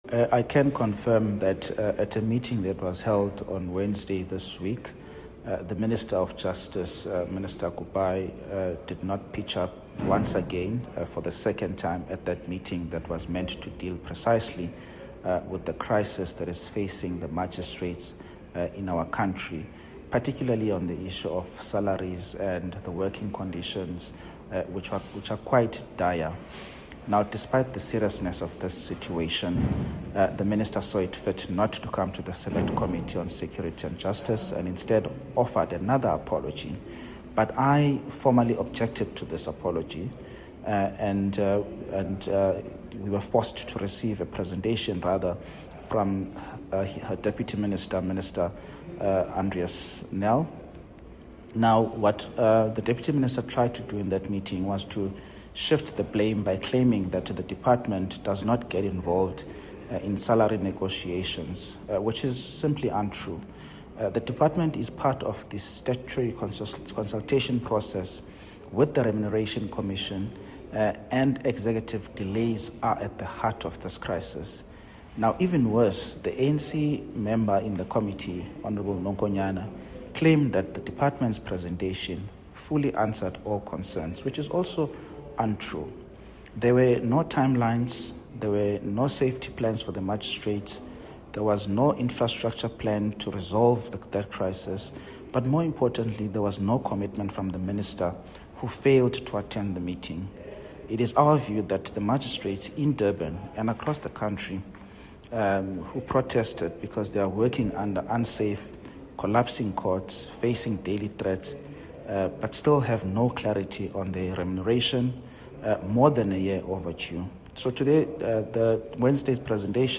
Soundbite by Mzamo Billy MP.